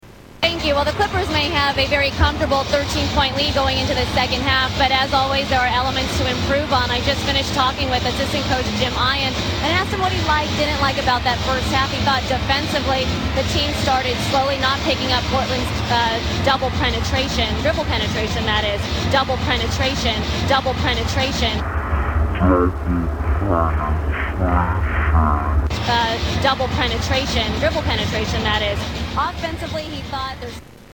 Double Penetration From Freudian Slips - Live on the News !
Tags: Media Freudian Slips News Newscasters Funny